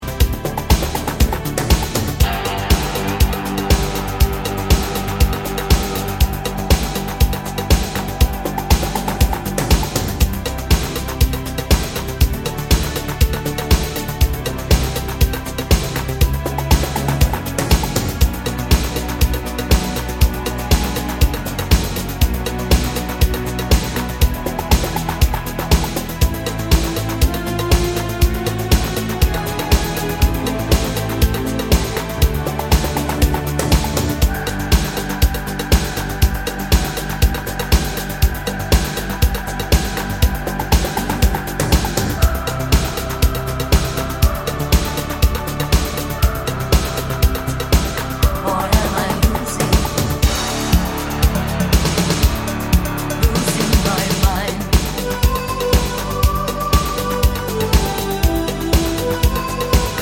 no Backing Vocals Musicals 4:00 Buy £1.50